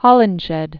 (hŏlən-shĕd, -ĭnz-hĕd) or Hol·lings·head (-ĭngz-hĕd), Raphael Died c. 1580.